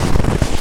High Quality Footsteps
STEPS Snow, Walk 22-dithered.wav